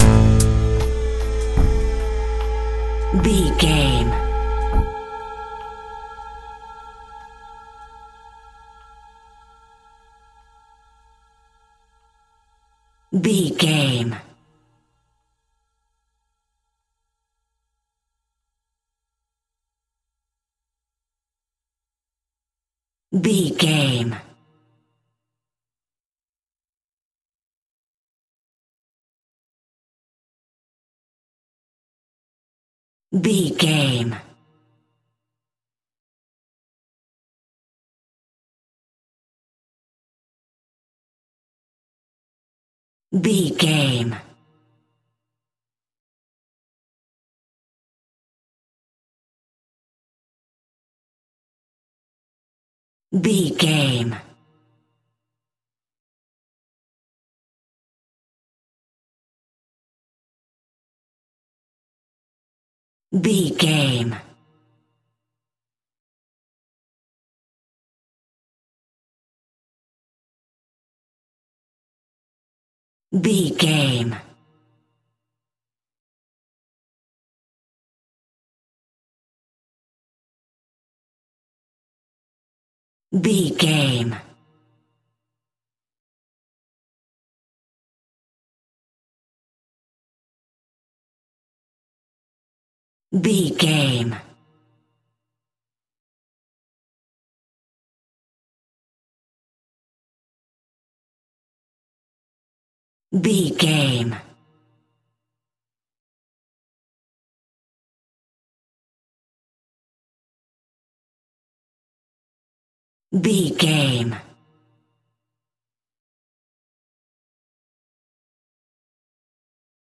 Aeolian/Minor
tension
ominous
dark
eerie
piano
electric piano
synthesiser
horror